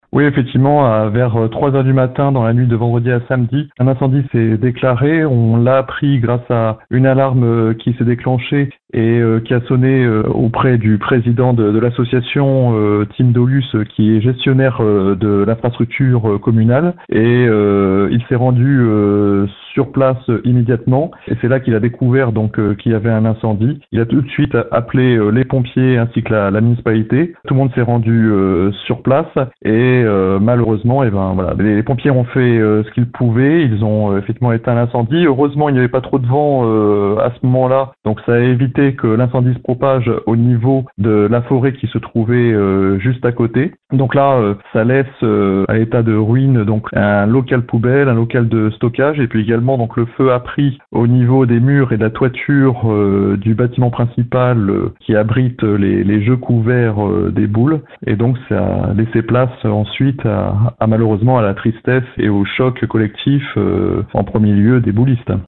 Aucun blessé n’est à déplorer, mais les dégâts sont importants, comme le souligne le maire Thibault Brechkoff, qui nous rappelle les faits :